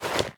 equip_leather2.ogg